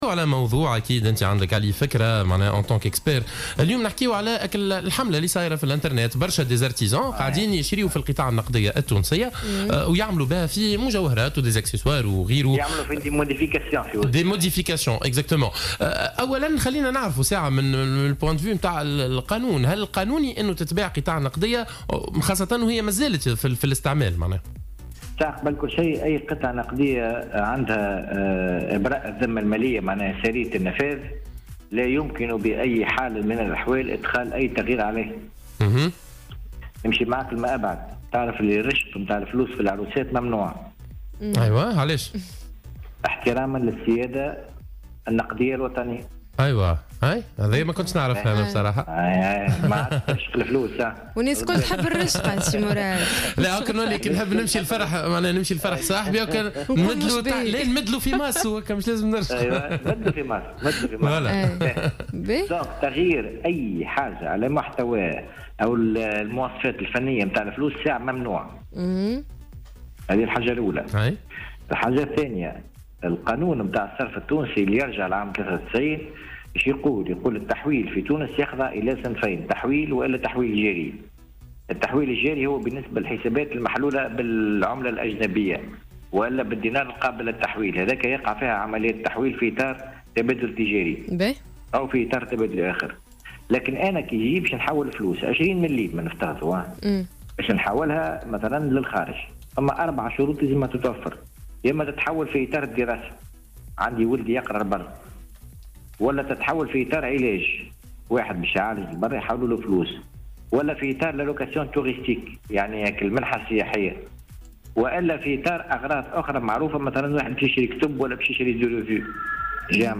في مداخلة له صباح اليوم في برنامج "صباح الورد" على "جوهرة أف أم"